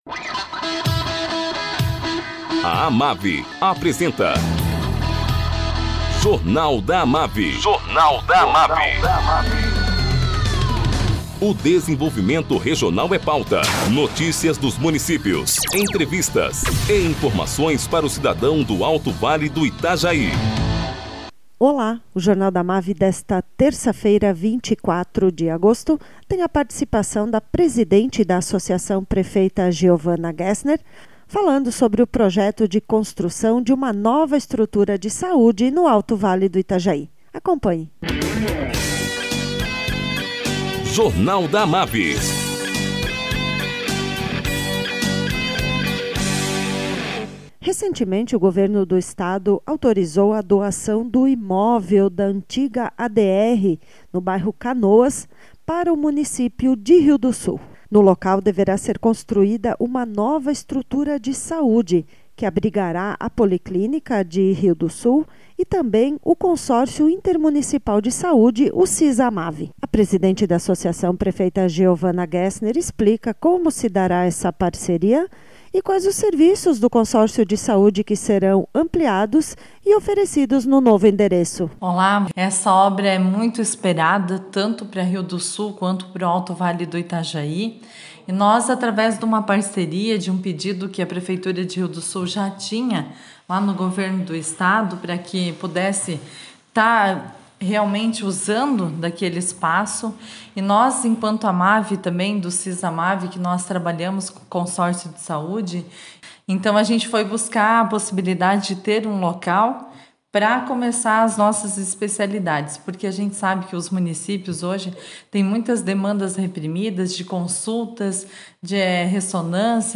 Entrevista com a presidente da AMAVI, prefeita Geovana Gessner, sobre o projeto de construção de uma nova estrutura de saúde no Alto Vale do itajaí